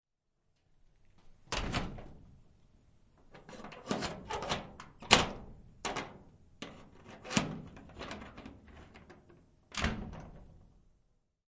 公共卫生间" 卫生间 入口门1
描述：公共盥洗室推门开关x2，门上的铰链吱吱作响，关闭时用力撞击门框。 背景是排气扇
标签： 锁存器 关闭 开放 squeeky PUCH门
声道立体声